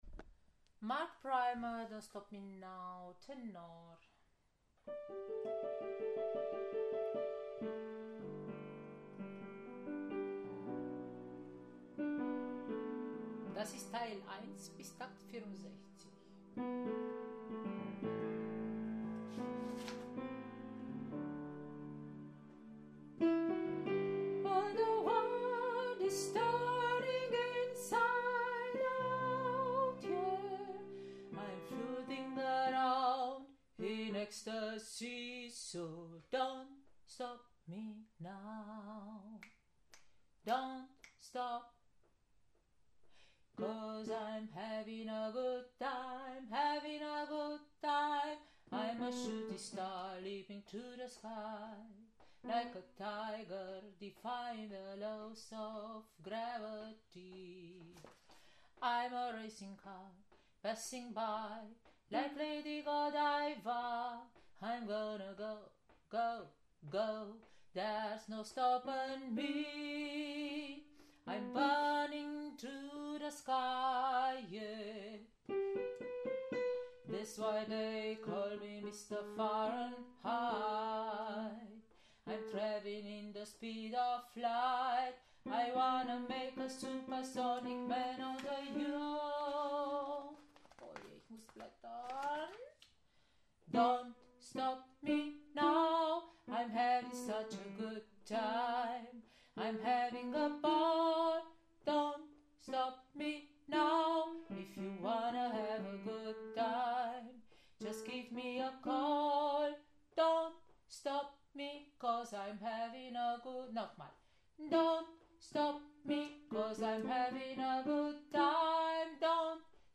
04 - Tenor - ChorArt zwanzigelf - Page 4